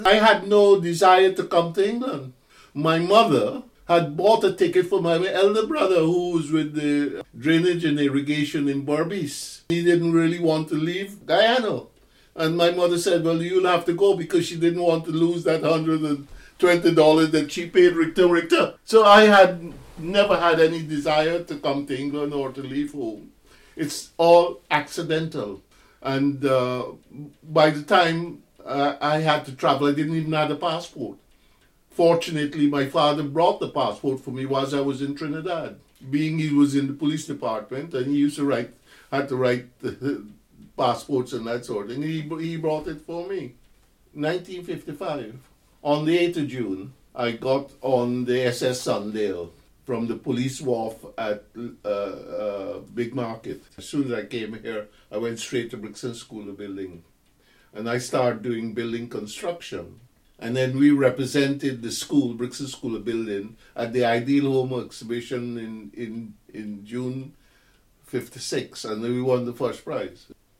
This oral history excerpt has been drawn from the three-year AHRC-funded project ‘The Windrush Scandal in a Transnational and Commonwealth Context’.